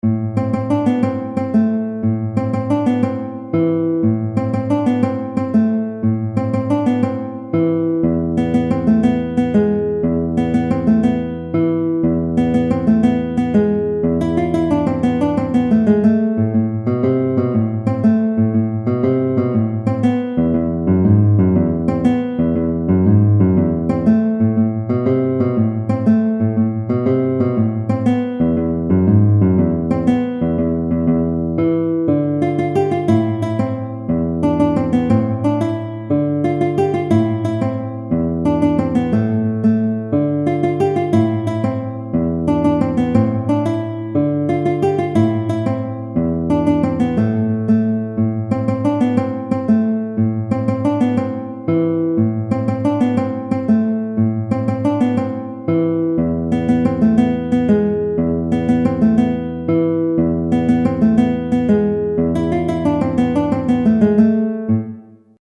Tarantella
Sololiteratur
Gitarre (1)